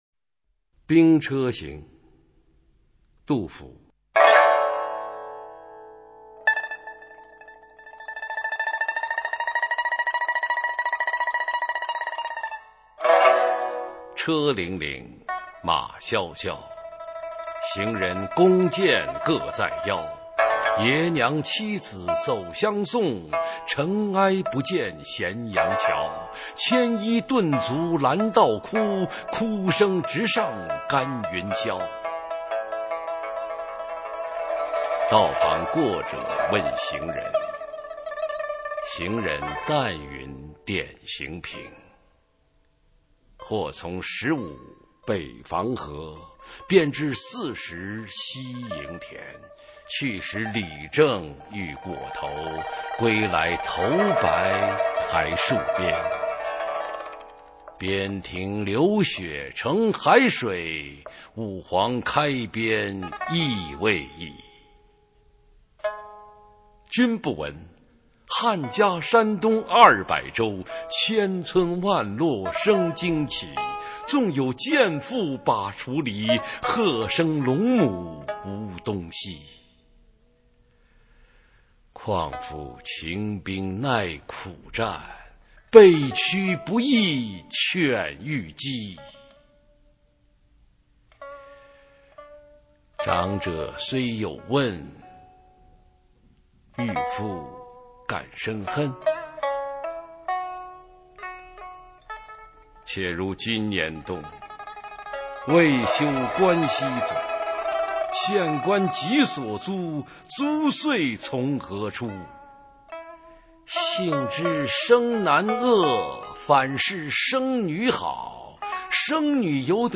普通话美声欣赏：兵车行